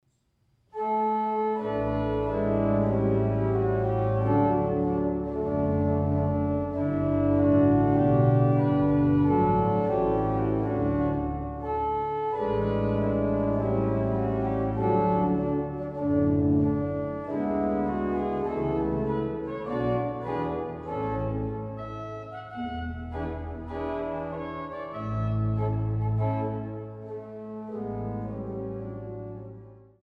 Stadtkirche St. Petri zu Löbejün